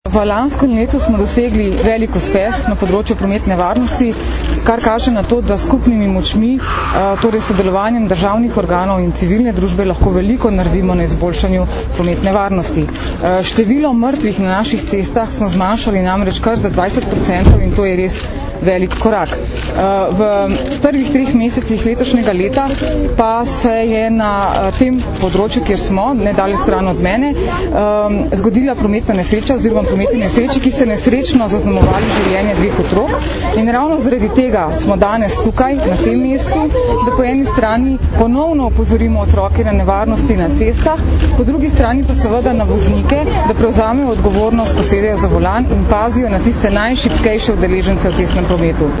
Ministrica za notranje zadeve Katarina Kresal in namestnica generalnega direktorja policije mag. Tatjana Bobnar sta danes, 5. aprila, skupaj z učenci OŠ Oskarja Kovačiča, vrhunskima športnikoma Tino Maze in Dejanom Zavcem ter ljubljanskimi policisti opozorili na varnost otrok v prometu.
Zvočni posnetek izjave mag. Tatjane Bobnar (mp3)